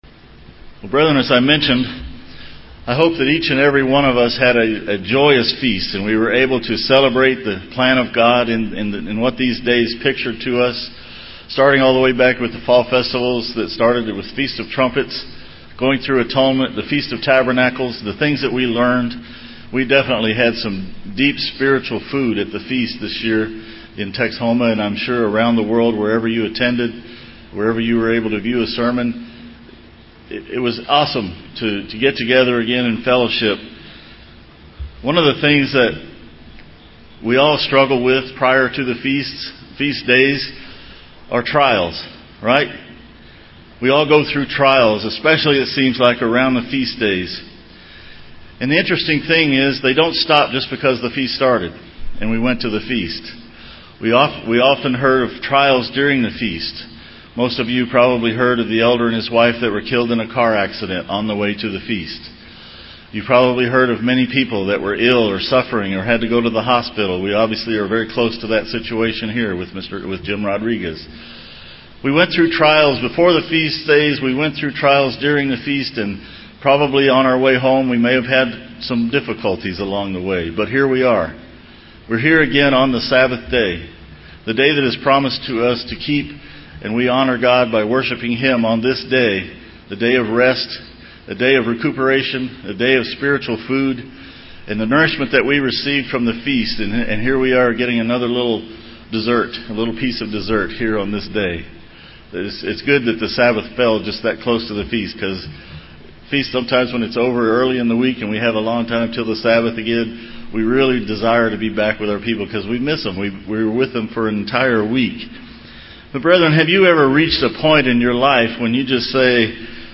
This sermon addresses trials and why we suffer them and give five tools to assist us when suffering trials.
Given in San Antonio, TX